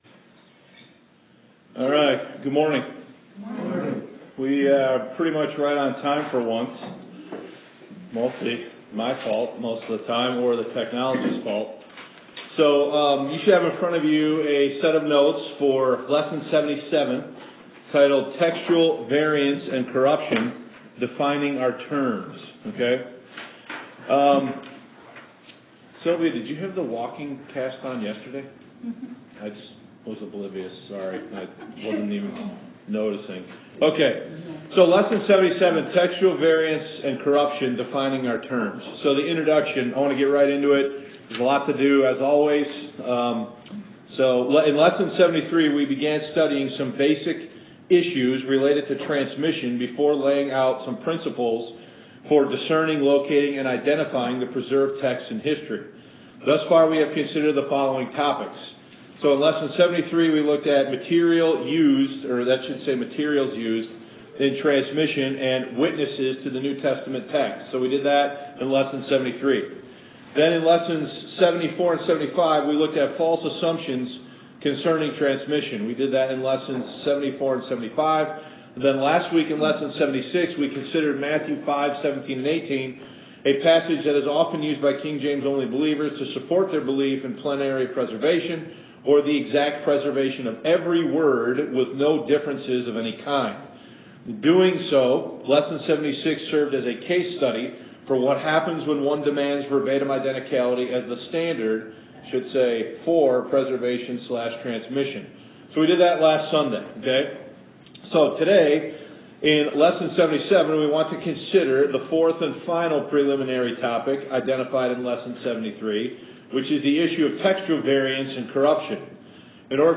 Please excuse the inconsistency in the recording. We were experimenting with some different setting on our camera.